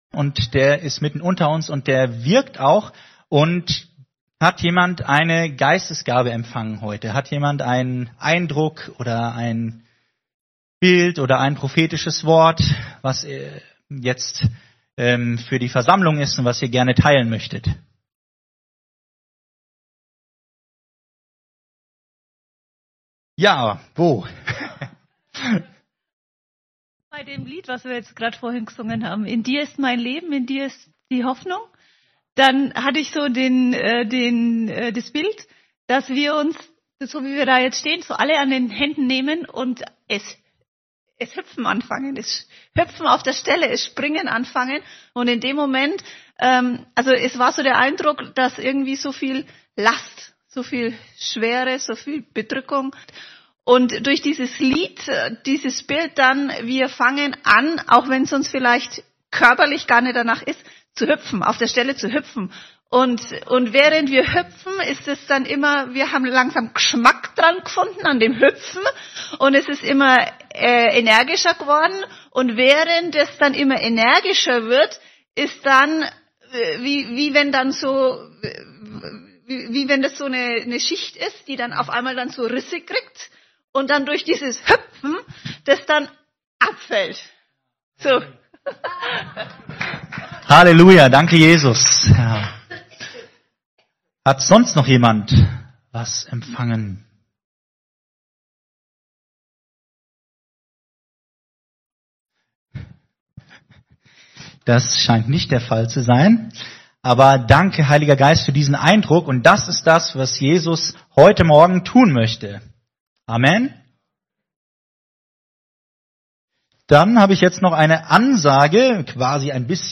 Predigten | Willkommen